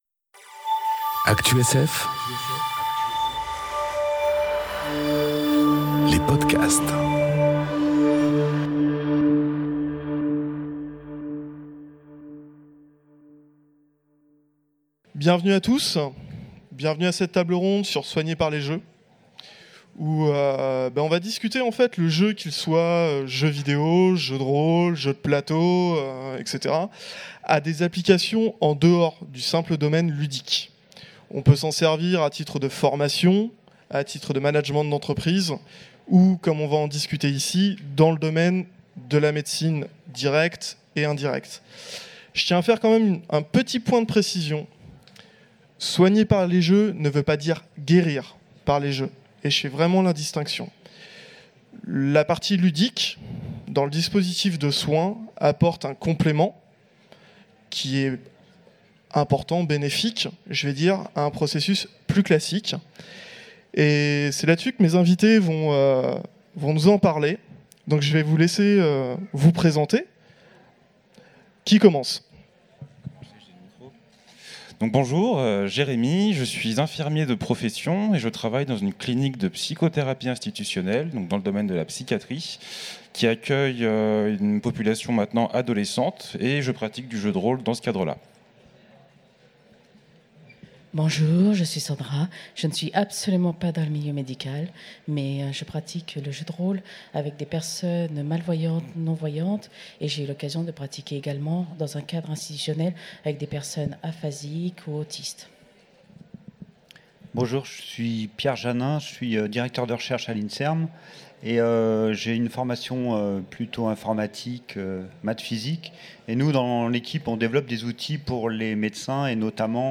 Conférence Soigner par les jeux enregistrée aux Utopiales 2018